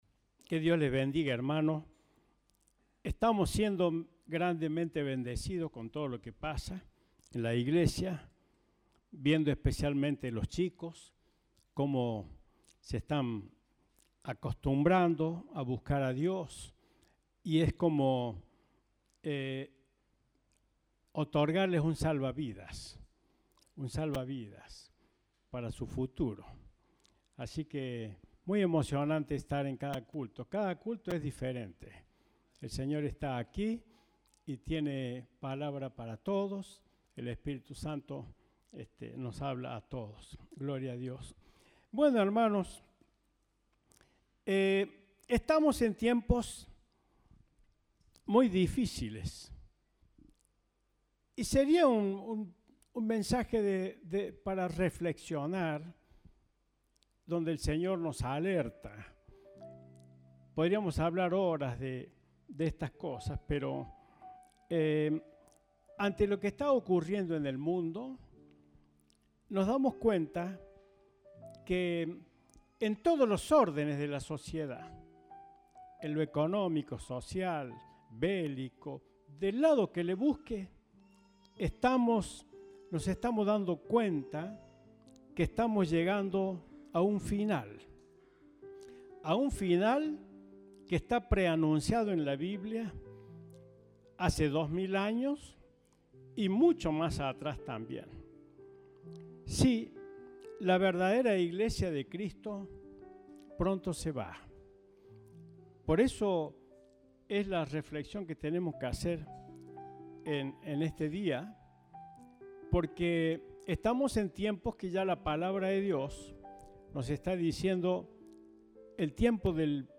Compartimos el mensaje del Domingo 6 de Noviembre de 2022.